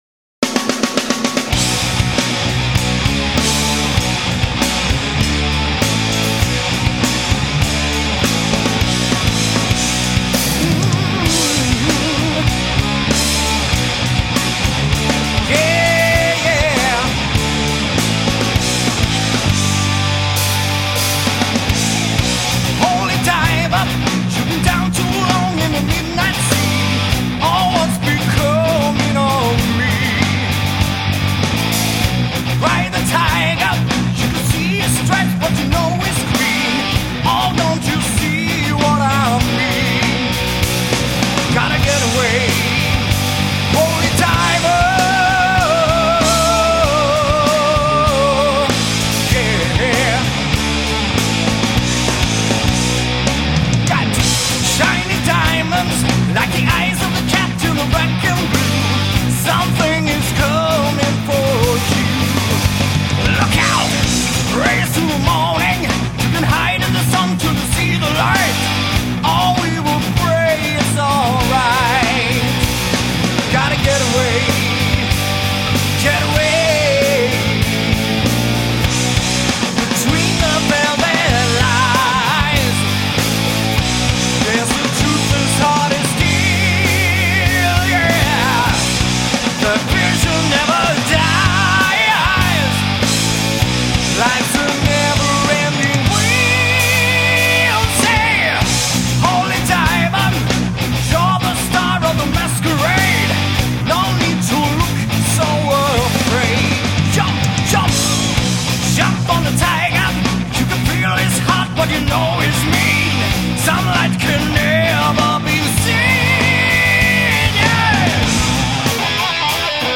Gitarr
Trummor